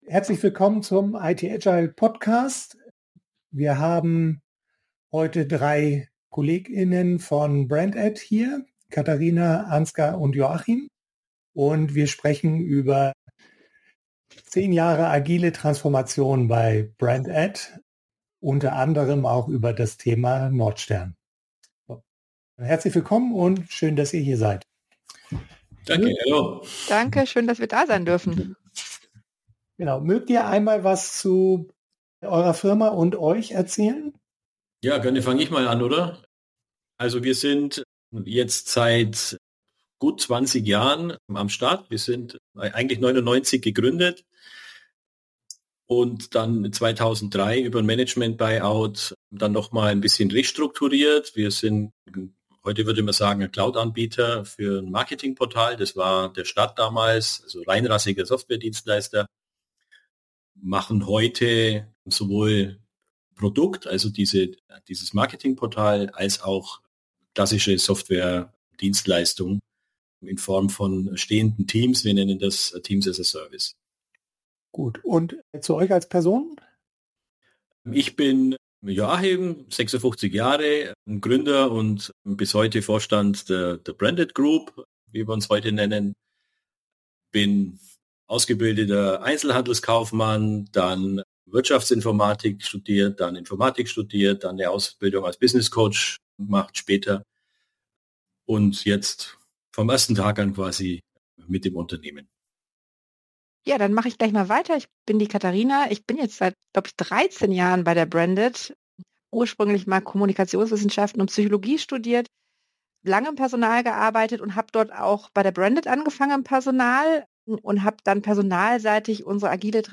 Agile Transformation mit dem Nordstern bei BRANDAD ~ Unsere Kunden im Interview Podcast